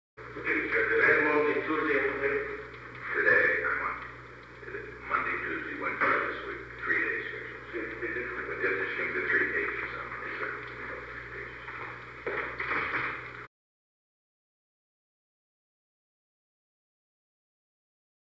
Conversation: 822-002
Recording Device: Oval Office
The Oval Office taping system captured this recording, which is known as Conversation 822-002 of the White House Tapes.